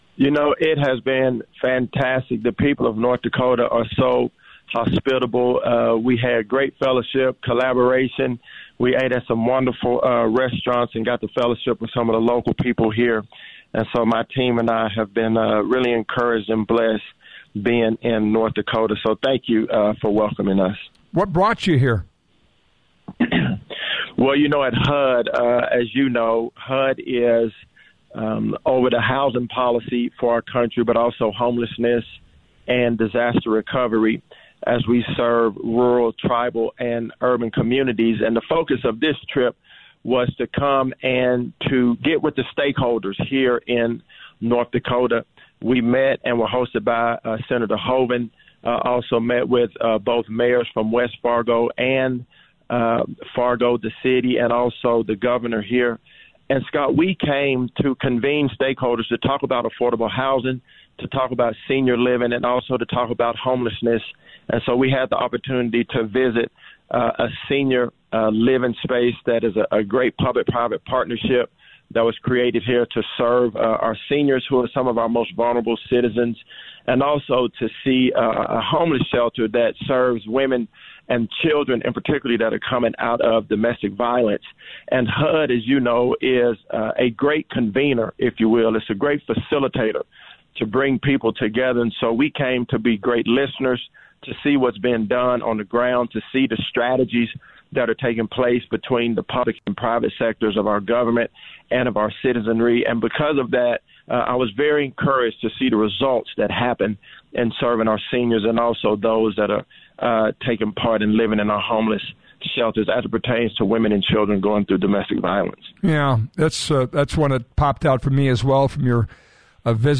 “It has been fantastic,” Turner said on The Flag on Friday.